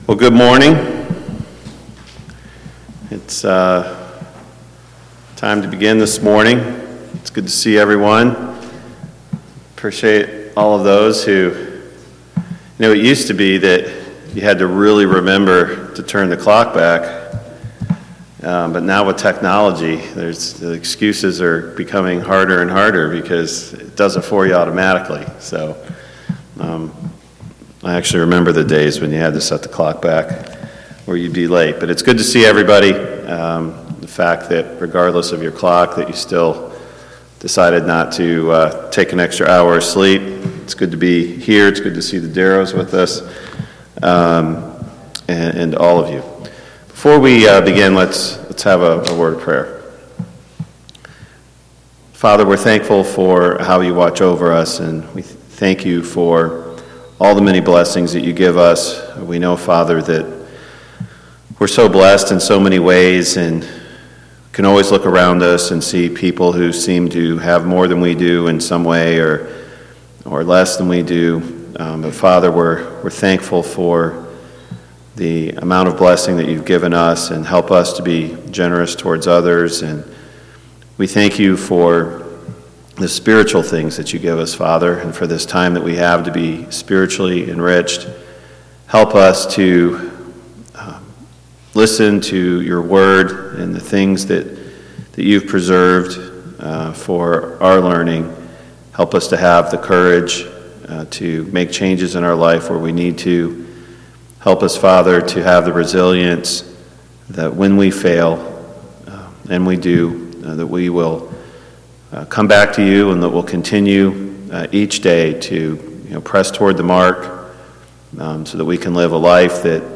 Series: A Study on Unity Passage: Genesis 4 Service Type: Sunday Morning Bible Class